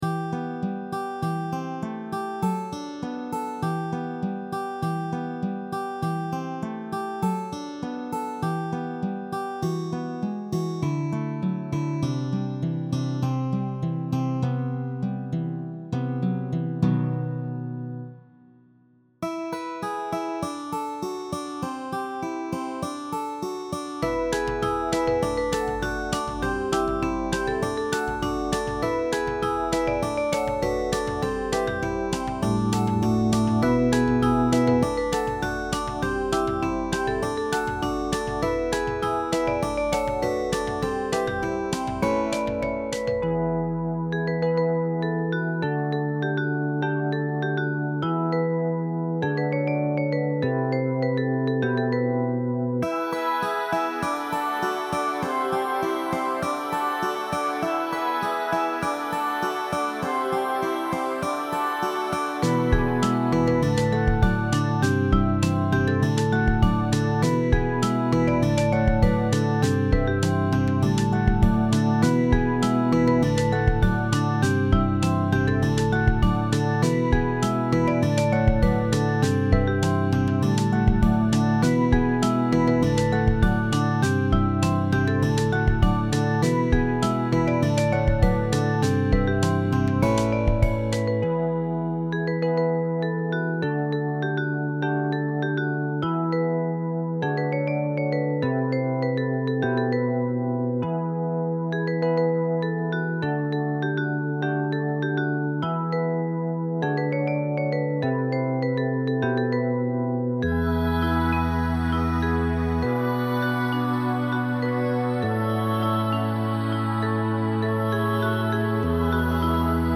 ProgRockBallad
보컬 파트를 위한 비브라폰